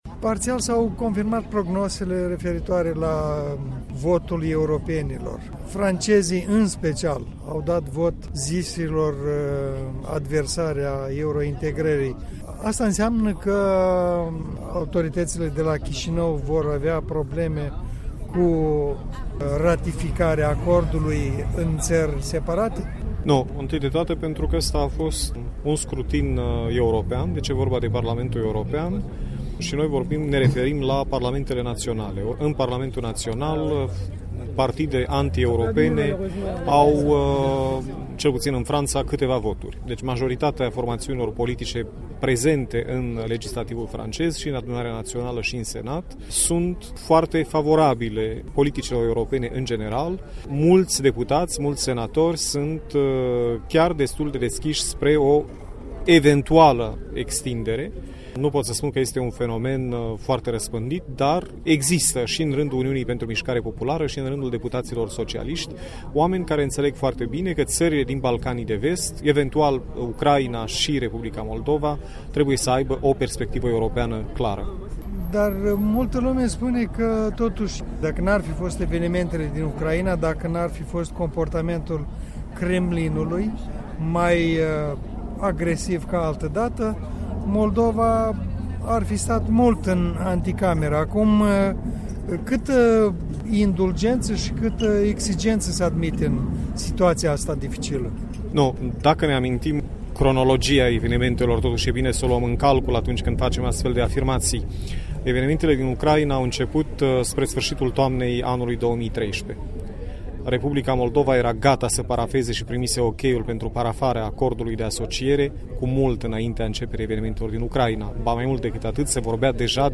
Ambasadorul Moldovei la Paris, Oleg Serebrian, în dialog